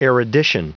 Prononciation du mot erudition en anglais (fichier audio)
Prononciation du mot : erudition